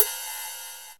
VEC3 Cymbals Ride 22.wav